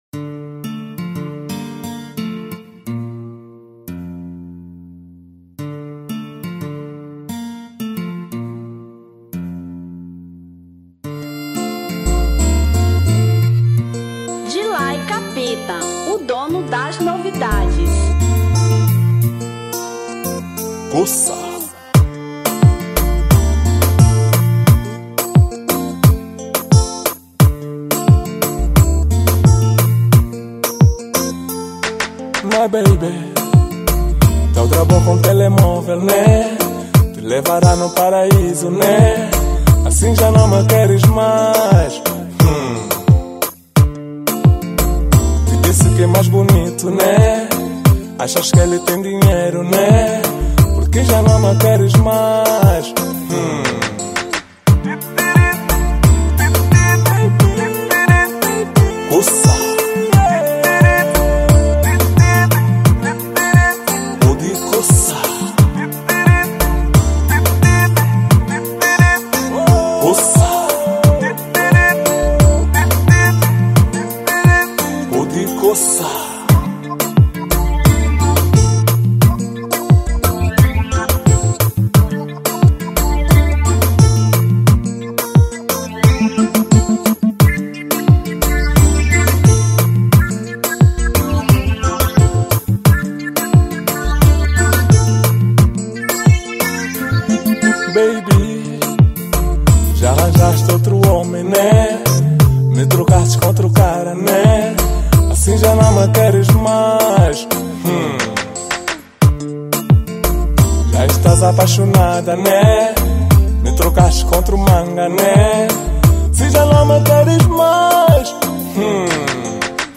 Kizomba 2005